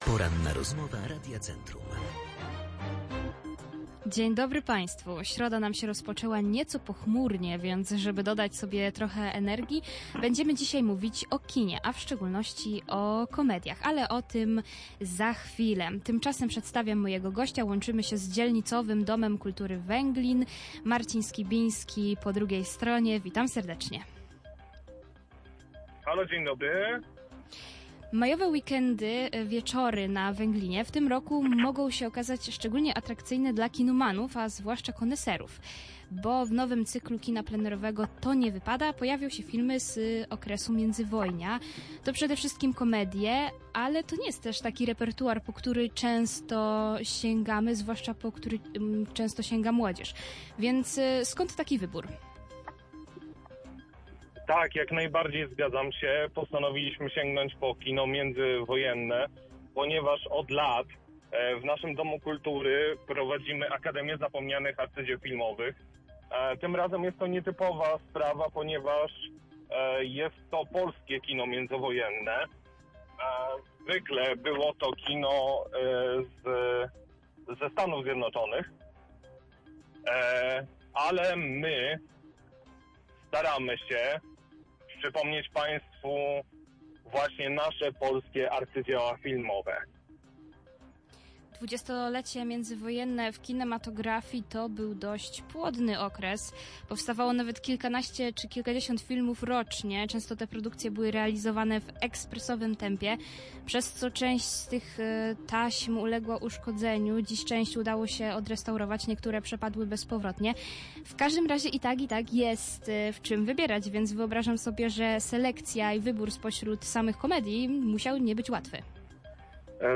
Cała rozmowa znajduje się poniżej: